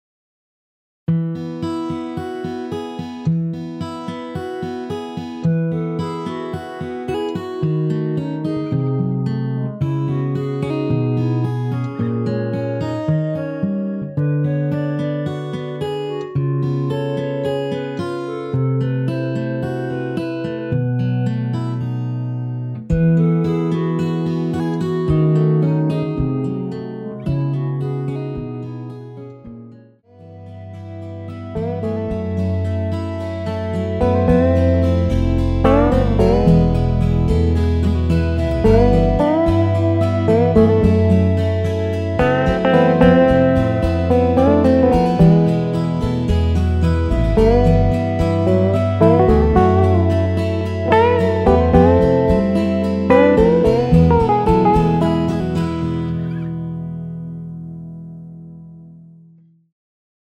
엔딩이 페이드 아웃이라 마지막 가사 “영영”을 4번 하고 엔딩을 만들었습니다.(미리듣기 참조)
원키 멜로디 포함된 MR입니다.
앞부분30초, 뒷부분30초씩 편집해서 올려 드리고 있습니다.
중간에 음이 끈어지고 다시 나오는 이유는